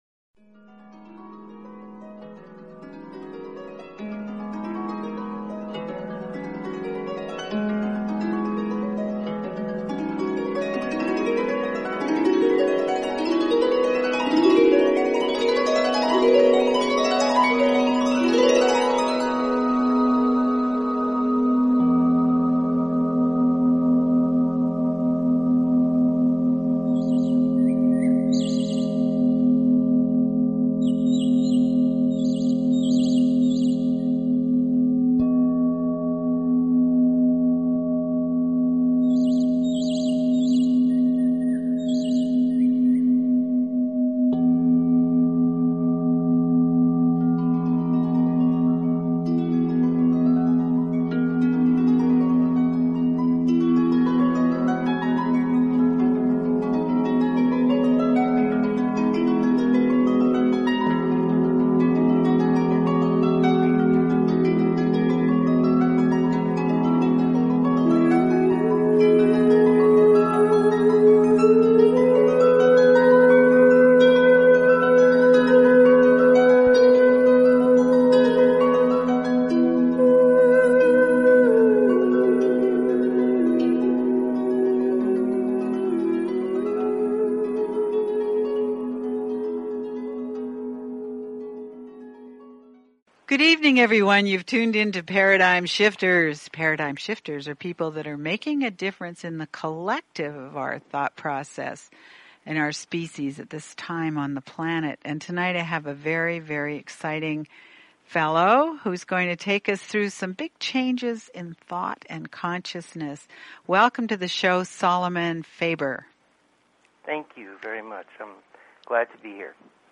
Talk Show Episode, Audio Podcast, Paradigm_Shifters and Courtesy of BBS Radio on , show guests , about , categorized as